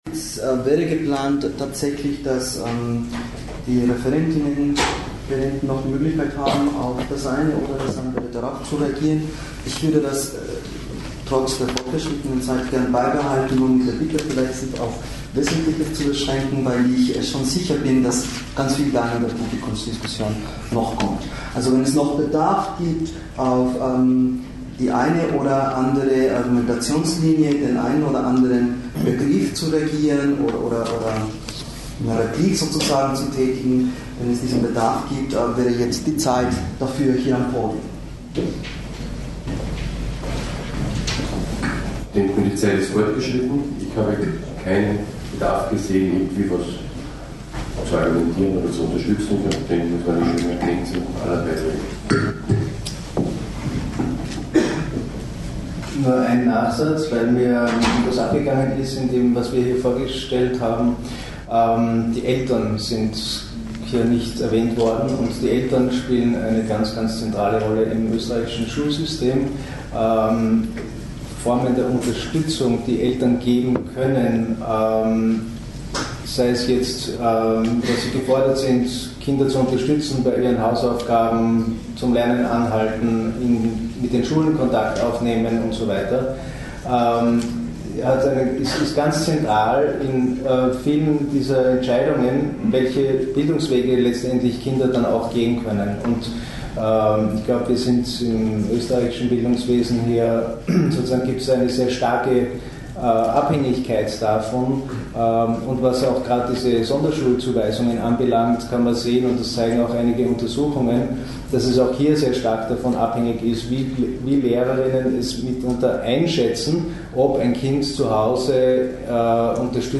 Diskussion ~ Österreichs Schulen brauchen inklusive Schulentwicklung Bedingungen, Fortschritte und Barrieren einer Politik der Vielfalt von Erziehung und Bildung Podcast
Die drei ReferentInnen werden bei dieser Veranstaltung ihre wissenschaftliche Expertise zu Fragen der Bildungssituation von Kindern und Jugendlichen mit Behinderungen sowie von Kindern und Jugendlichen mit Migrationsgeschichte einbringen und mit dem Publikum disku- tieren.
3_diskussion.mp3